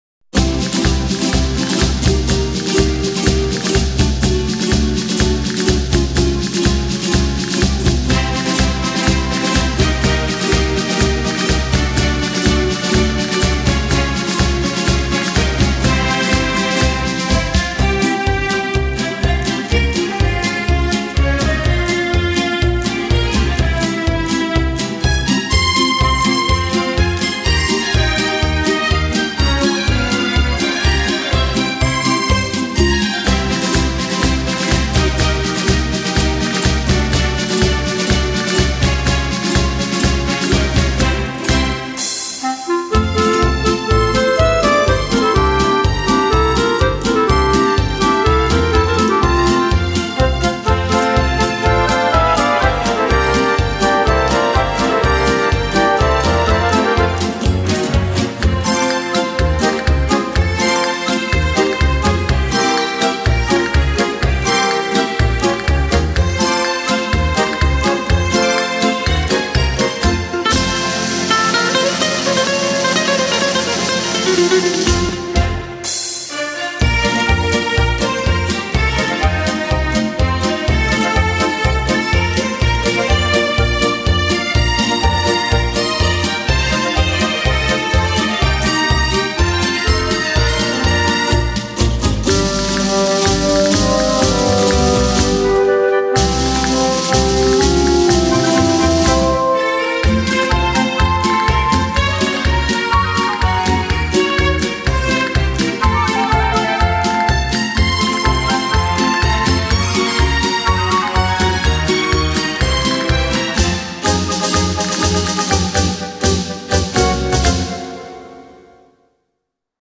01 Paso Double